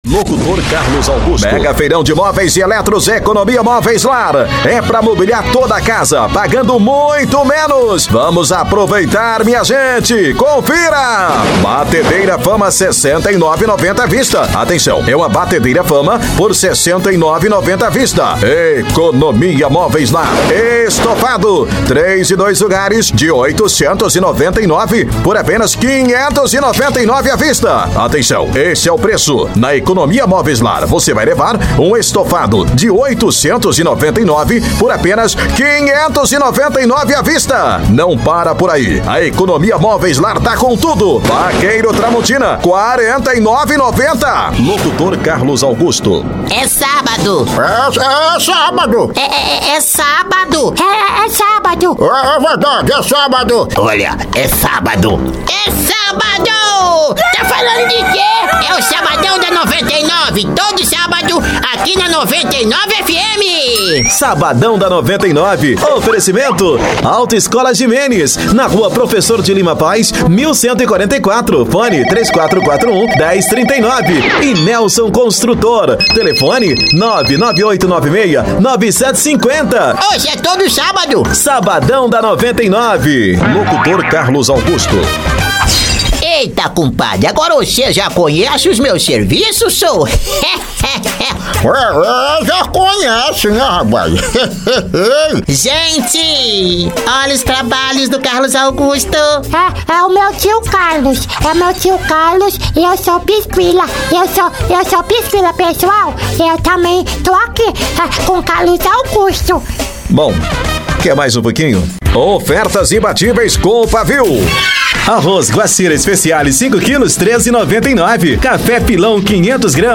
Spot Comercial
Vinhetas
Padrão
Animada
Caricata